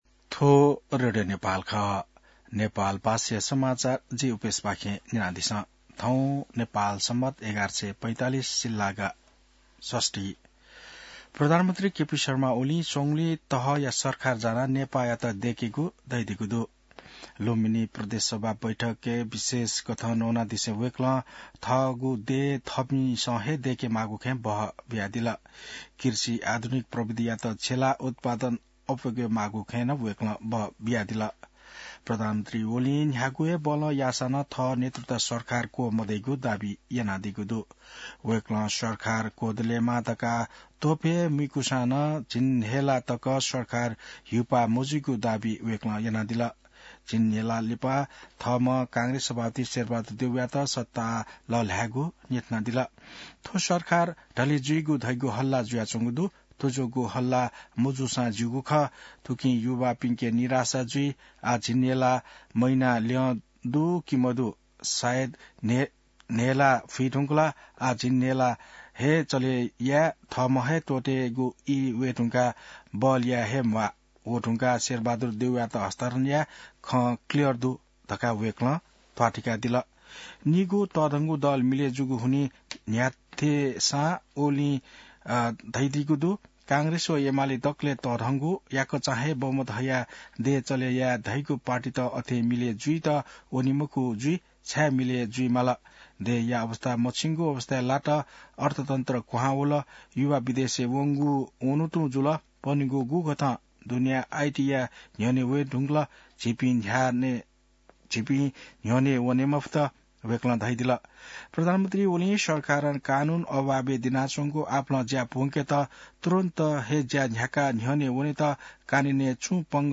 नेपाल भाषामा समाचार : ७ फागुन , २०८१